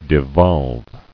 [de·volve]